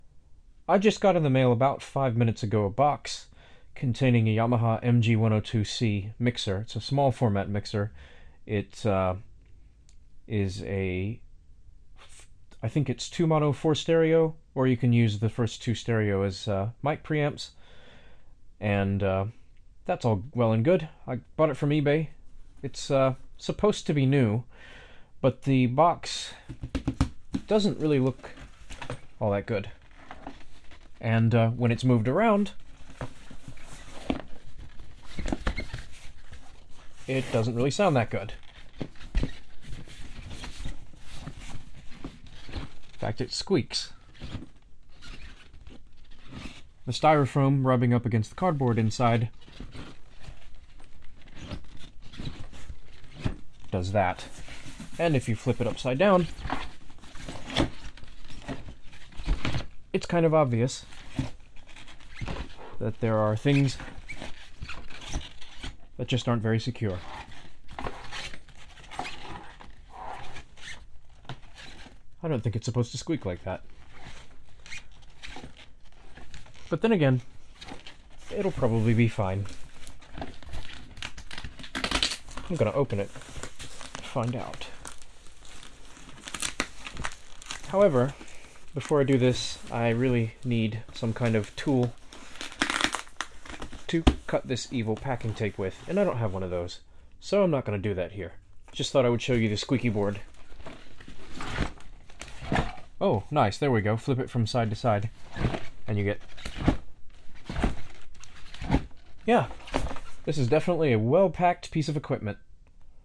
I just received a box containing a Yamaha MG102C small format mixer. This is the pre-unboxing experience. Recorded with the iPhone 5, Tascam IXJ2, and Roland CS10-EM microphones.